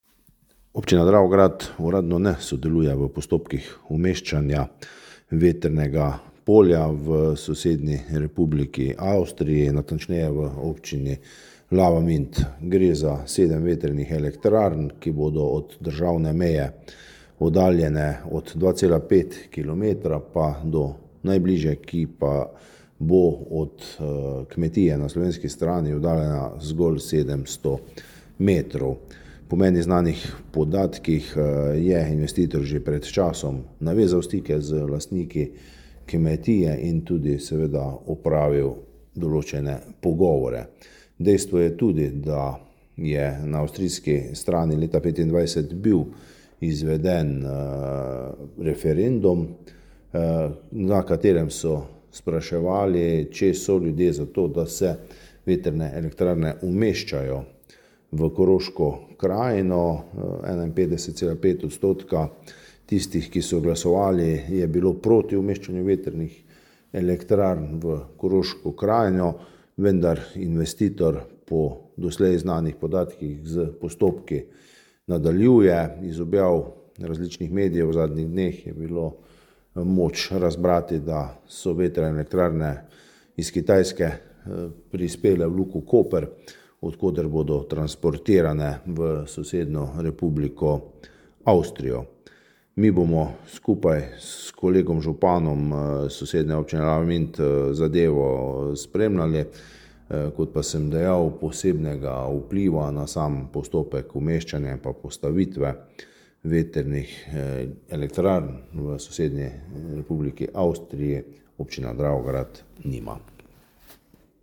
Izjava župana občine Dravograd Antona Preksavca: